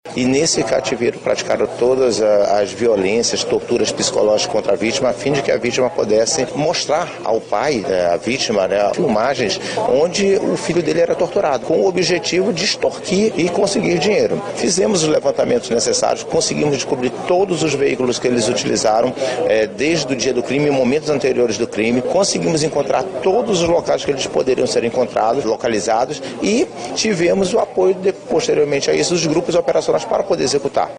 SONORA02_POLICIA.mp3